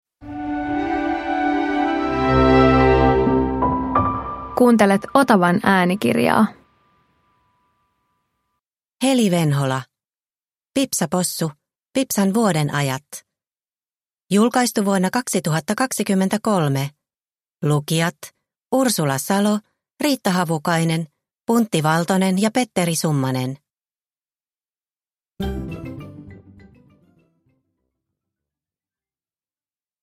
Pipsa Possu - Pipsan vuodenajat – Ljudbok – Laddas ner